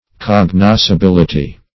Search Result for " cognoscibility" : The Collaborative International Dictionary of English v.0.48: Cognoscibility \Cog*nos`ci*bil"i*ty\, n. The quality of being cognoscible.